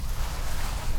fastroping_rope.ogg